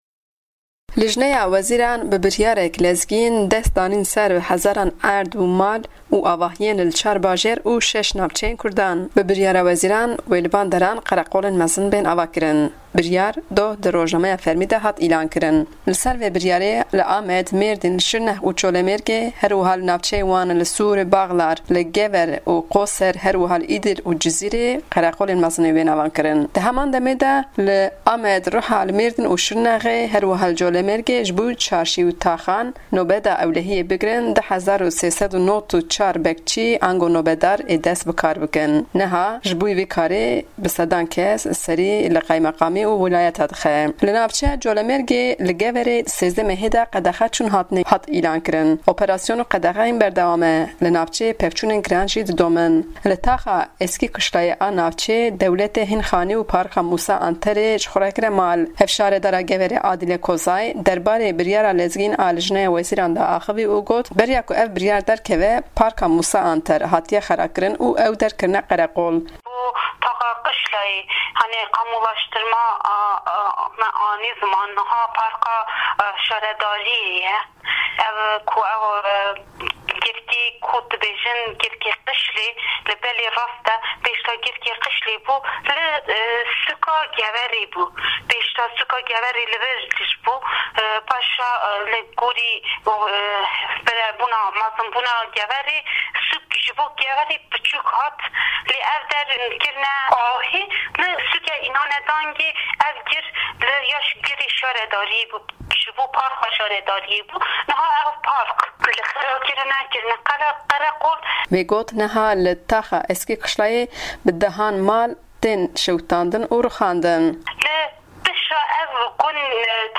Hevşaredara Geverê Adile Kozay, derbarê biryara lezgîn ya hikûmetê ji Dengê Amerîka re axivî.